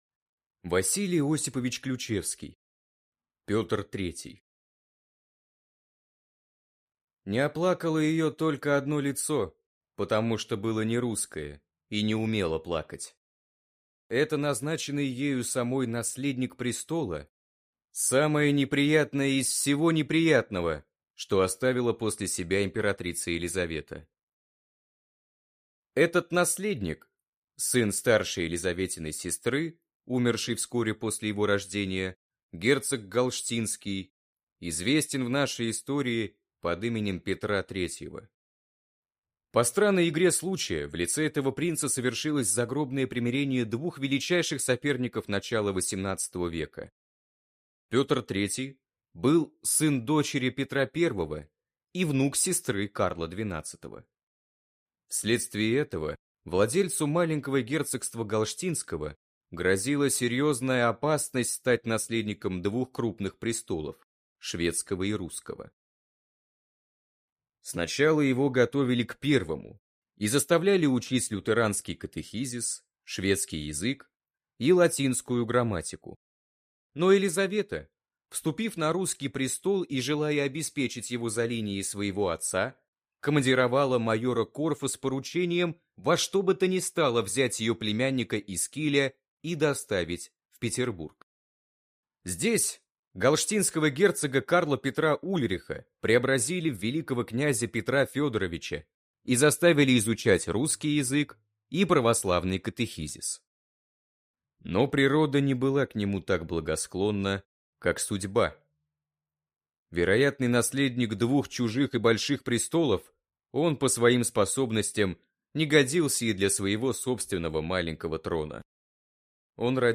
Аудиокнига Петр III | Библиотека аудиокниг